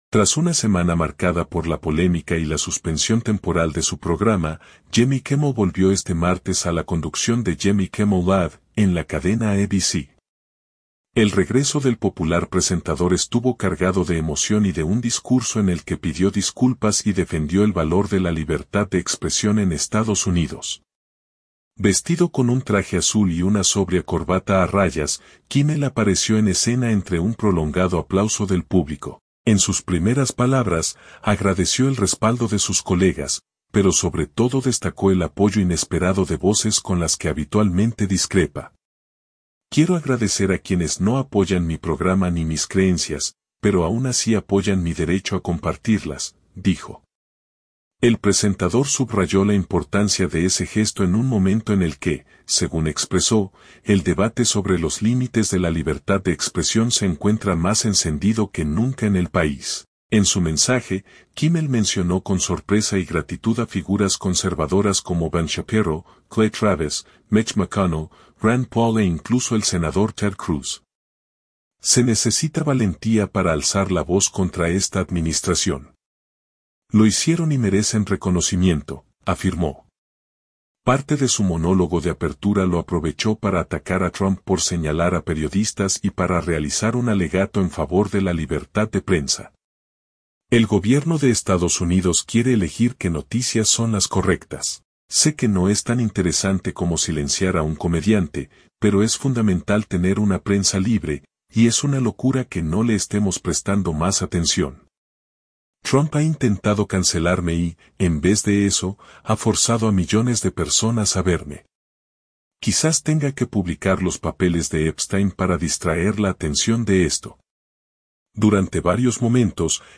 Vestido con un traje azul y una sobria corbata a rayas, Kimmel apareció en escena entre un prolongado aplauso del público.
Durante varios momentos, el conductor se mostró visiblemente conmovido.